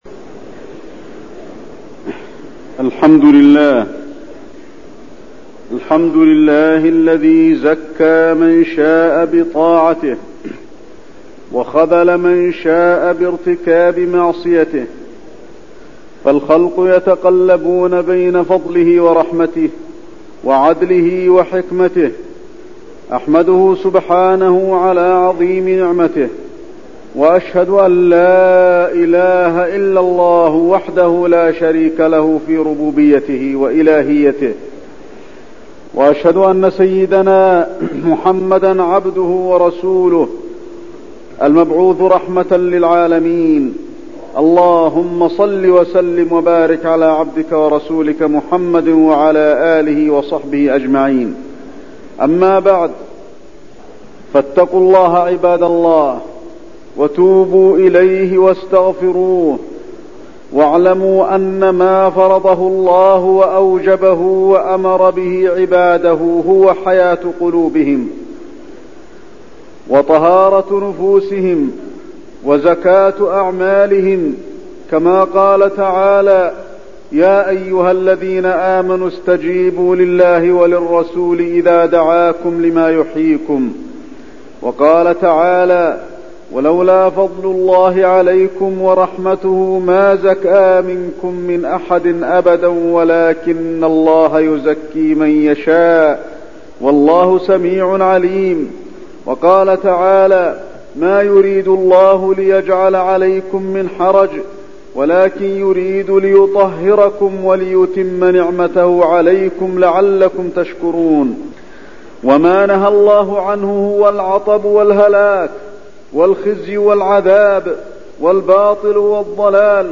تاريخ النشر ٢٥ ذو القعدة ١٤٠٦ هـ المكان: المسجد النبوي الشيخ: فضيلة الشيخ د. علي بن عبدالرحمن الحذيفي فضيلة الشيخ د. علي بن عبدالرحمن الحذيفي الفروض والواجبات